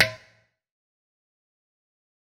SouthSide Snare Roll Pattern (28).wav